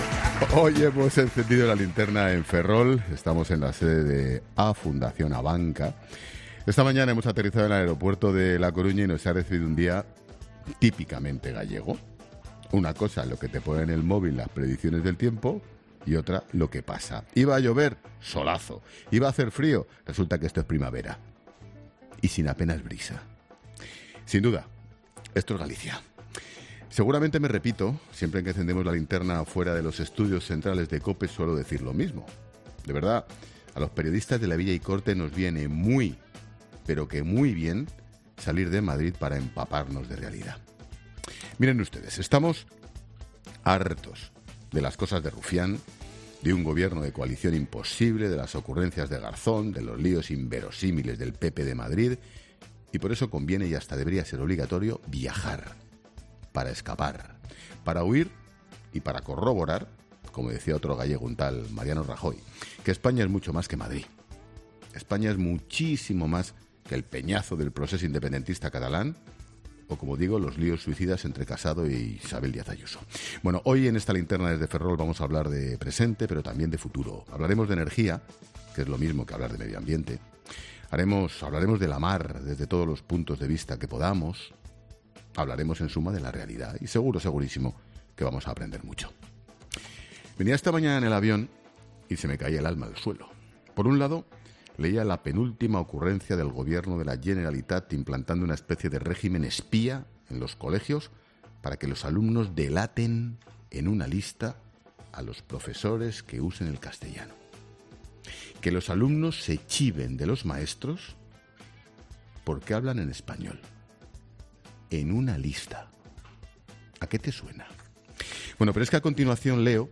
Monólogo de Expósito
El director de 'La Linterna' enciende el programa desde Ferrol, desde donde analiza las noticias más importantes de los últimos días